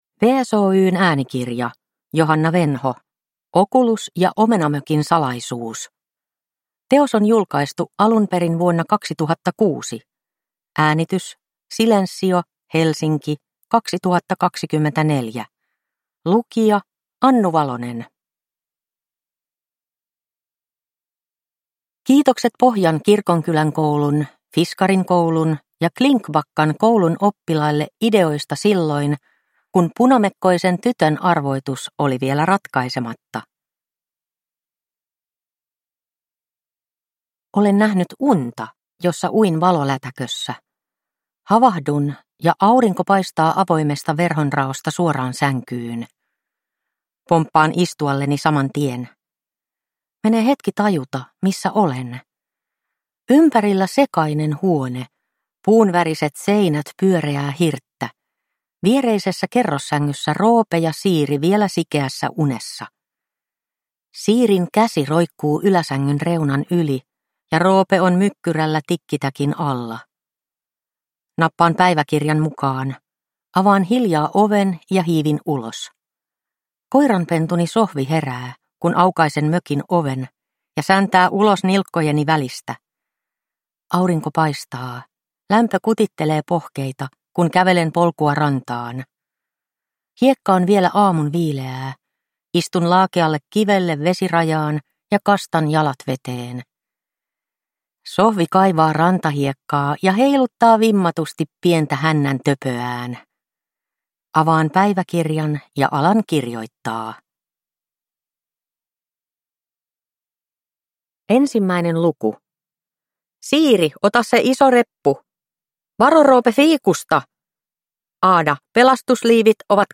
Okulus ja Omenamökin salaisuus – Ljudbok